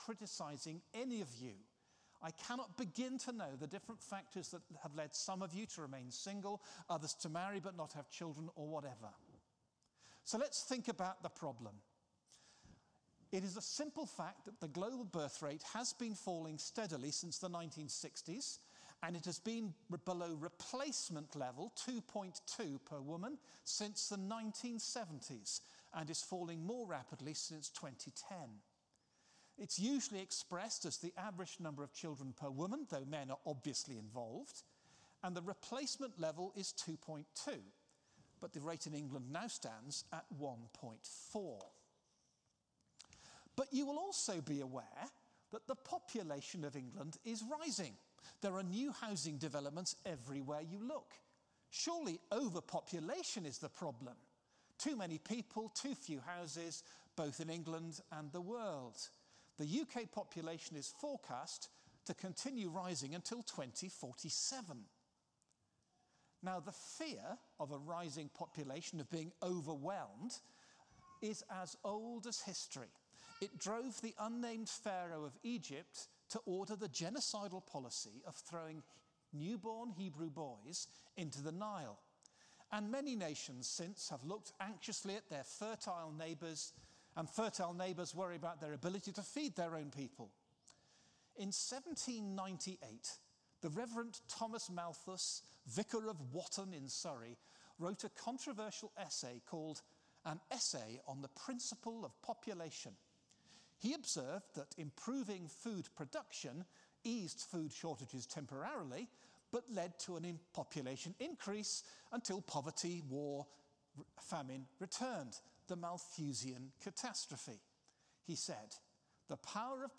Apologies, but the first few minutes of this talk weren’t recorded.
Exodus 1:15-2:10 Service Type: Sunday Service Apologies